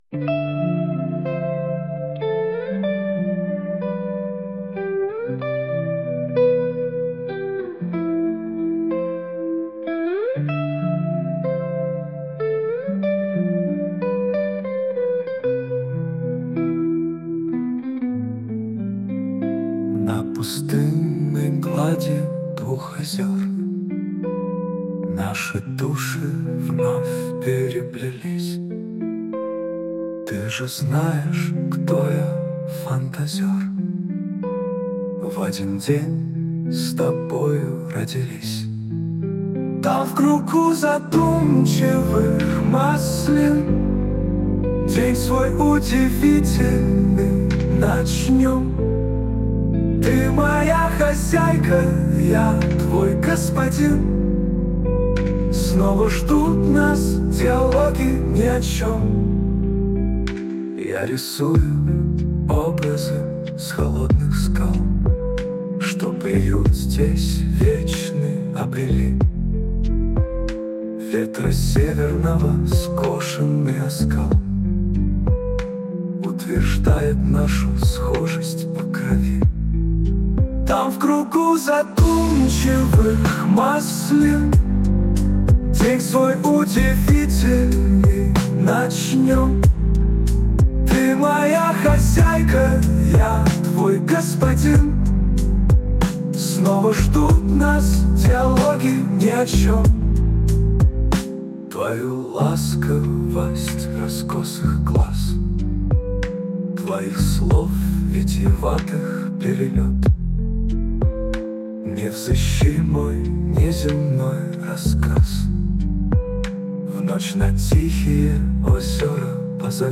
ТИП: Пісня
СТИЛЬОВІ ЖАНРИ: Романтичний